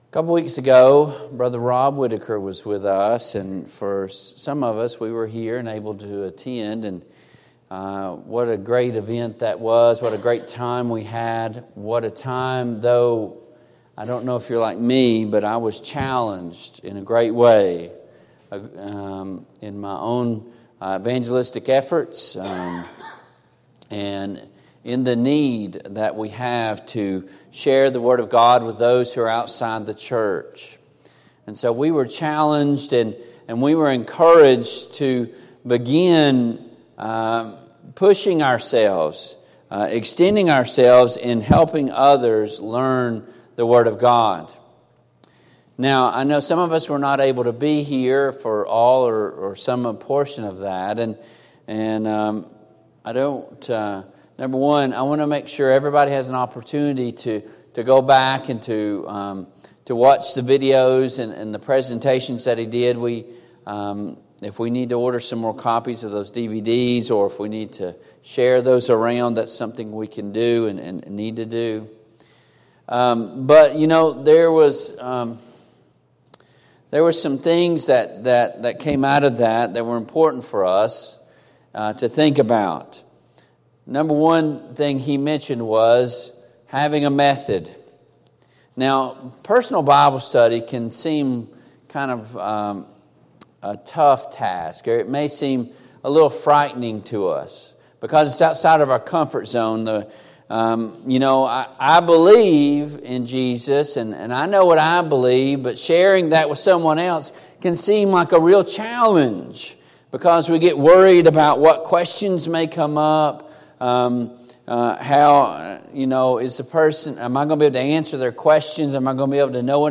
Service Type: PM Worship